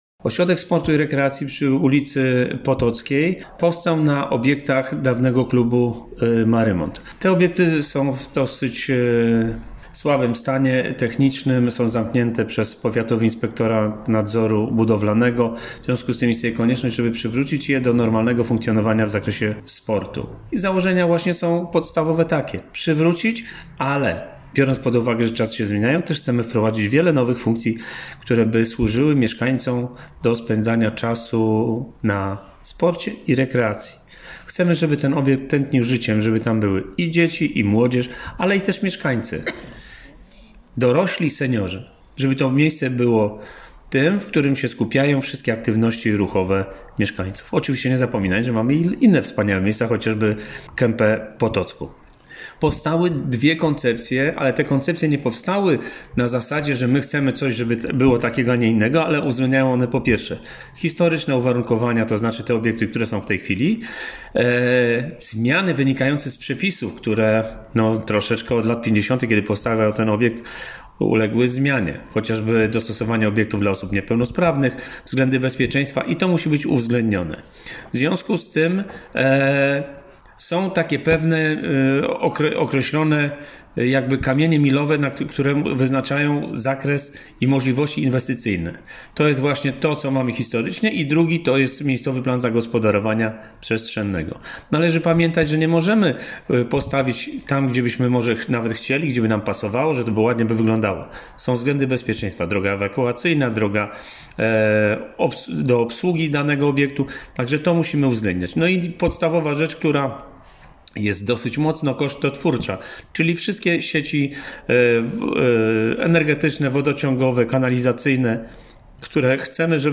Burmistrz Żoliborza Paweł Michalec: